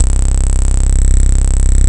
87 D50 BAS-R.wav